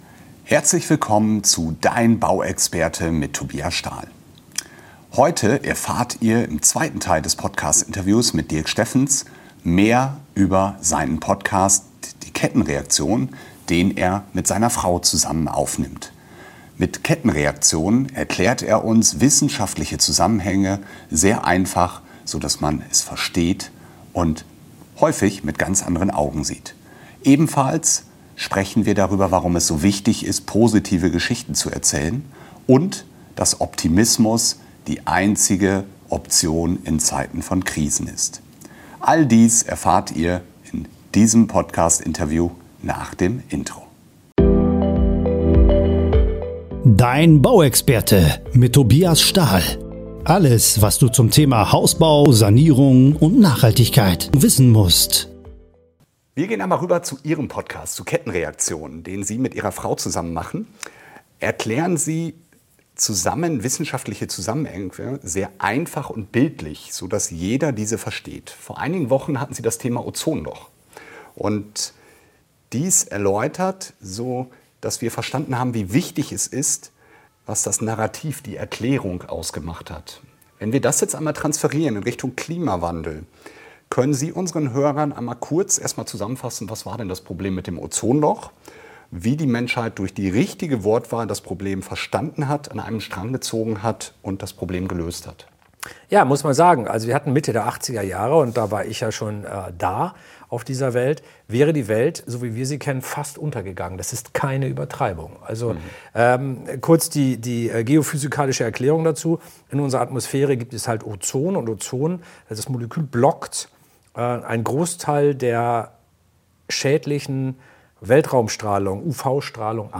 Nr. 151 - Interview mit Dirk Steffens - Hoffnungslos optimistisch (Teil 2) ~ Dein Bauexperte